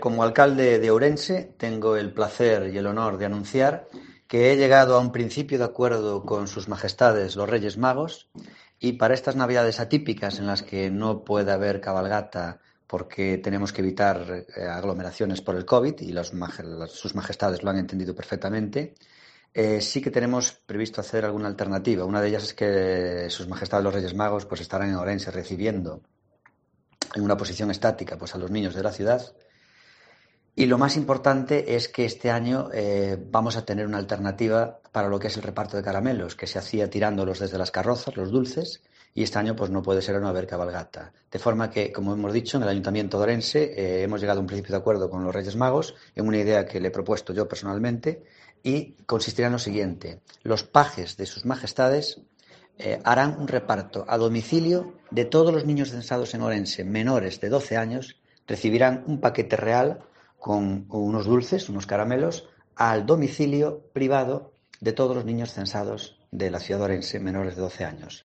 Declaraciones de Gonzalo Pérez Jácome sobre la alternativa a la Cabalgata de Reyes